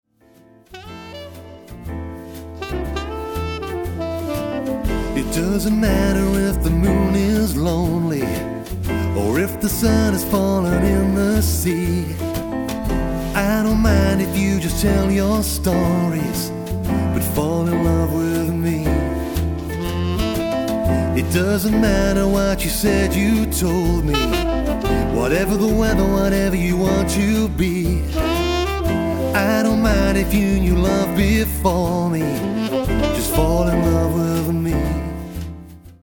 with vocals
Cool and classy lounge sounds